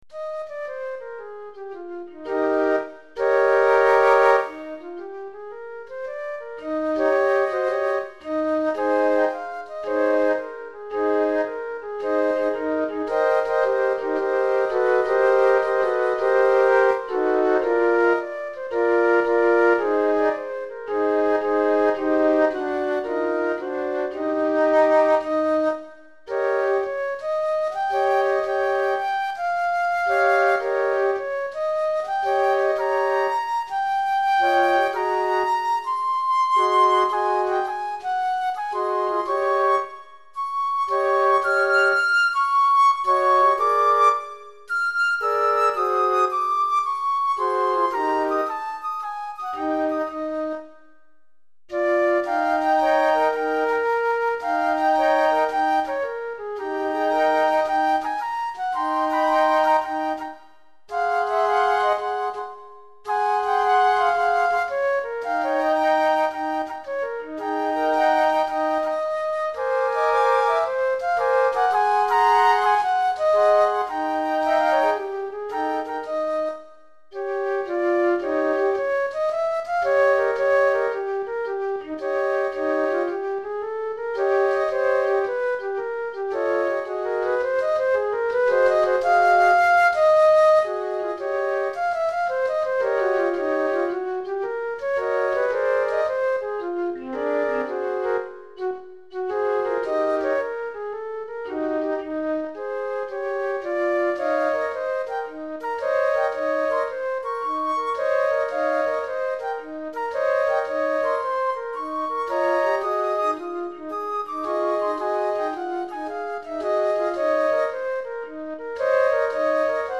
4 Flûtes Traversières